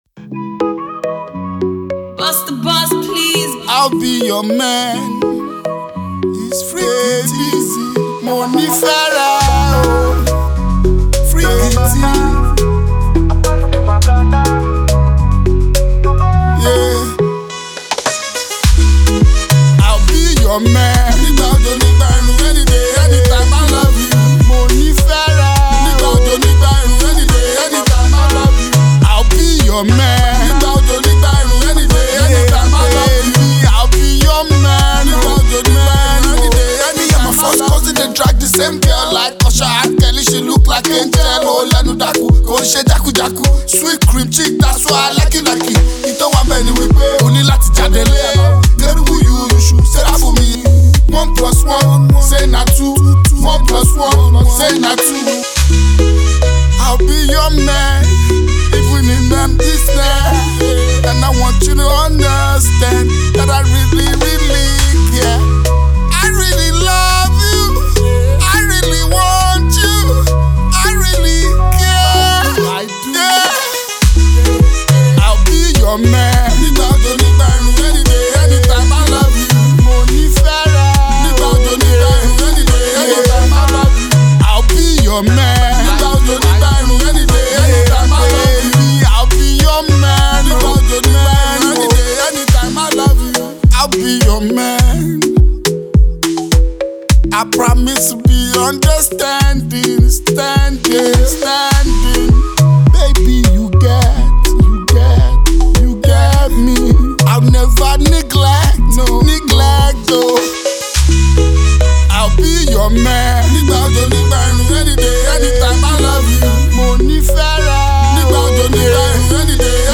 With its soothing vibe and well-crafted instrumentation